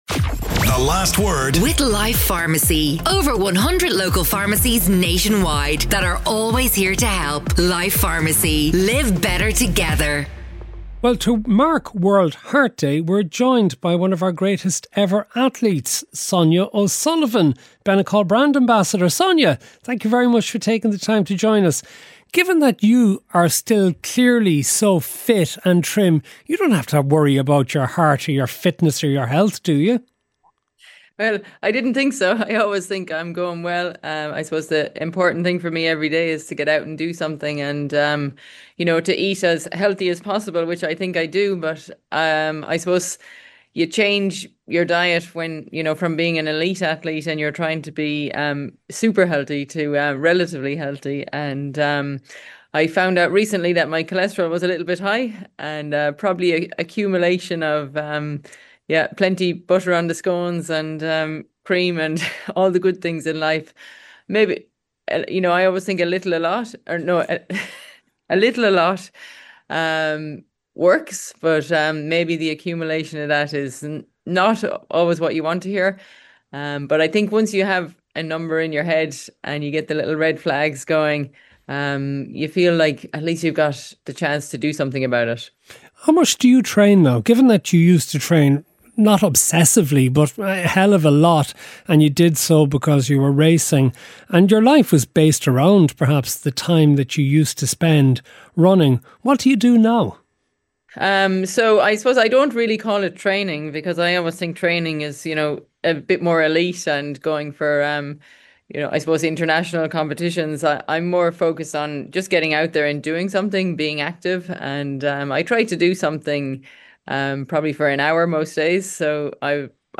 On Ireland's most cutting edge current affairs show, Matt and his guests provide a running stream of intelligent opinions and heated debates on the issues that matter most to Irish listeners.